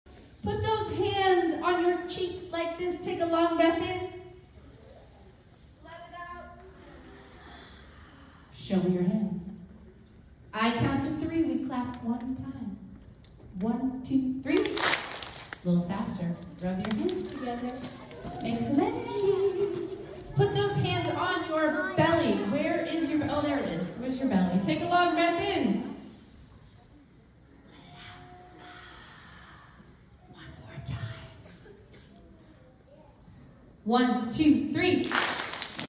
🌟 Here’s “1-2-3 Clap” with 250 kindergarteners. They were wiggly, chatty and squirrelly when they came in, but this got them calm and focused in less than a minute. 😊 👏It’s incredibly effective to incorporate music & rhythm into mindfulness.